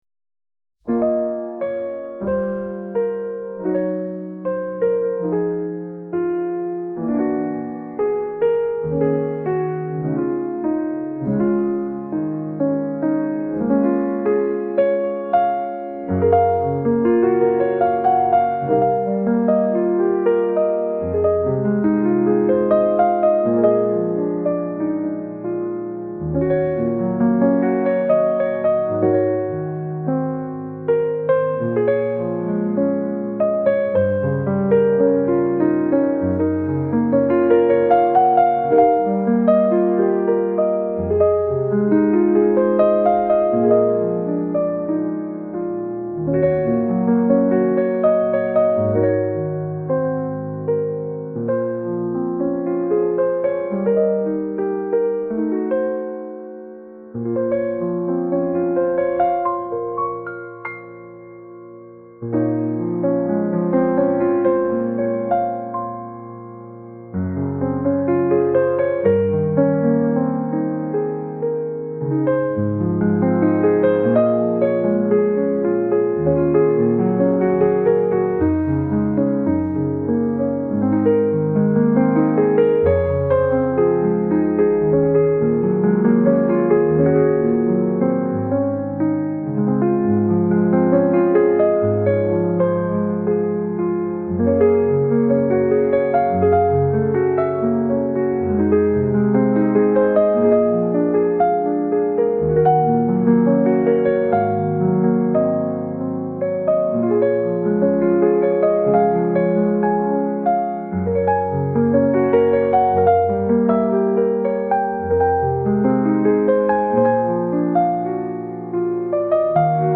バラード ピアノ 悲しい 懐かしい 穏やか